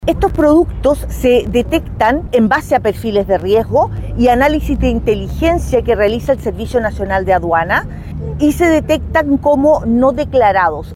Por su parte, la directora nacional (s) del Servicio Nacional de Aduanas, María Jazmín Rodríguez, indicó que estos productos se detectan en base a perfiles de riesgo y a análisis de inteligencia que realiza Aduanas.